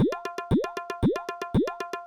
116 BPM Beat Loops Download